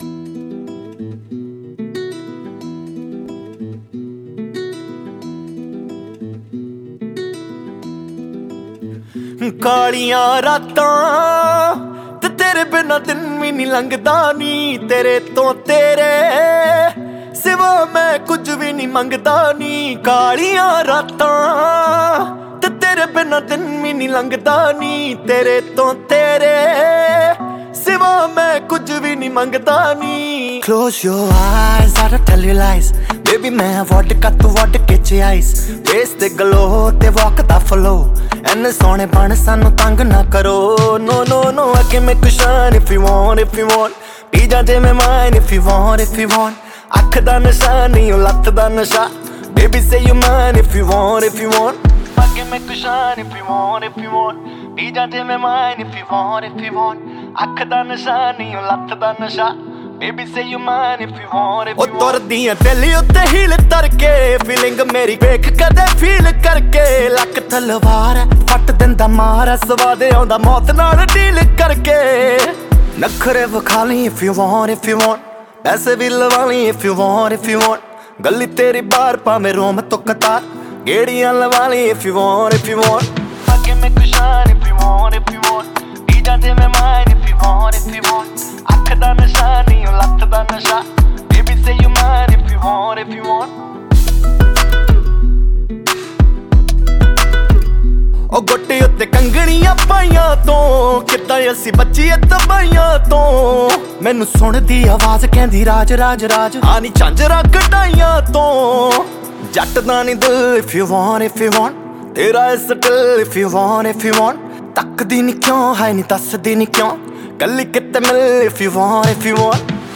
Punjabi Music